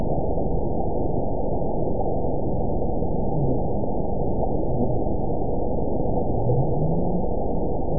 event 922100 date 12/26/24 time 11:00:53 GMT (4 months, 1 week ago) score 9.47 location TSS-AB04 detected by nrw target species NRW annotations +NRW Spectrogram: Frequency (kHz) vs. Time (s) audio not available .wav